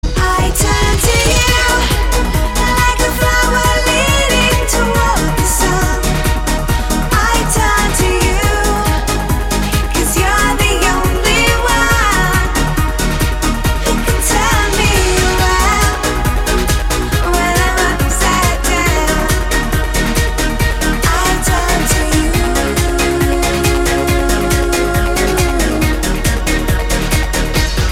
поп
громкие
женский вокал
dance